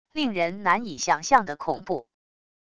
令人难以想象的恐怖wav音频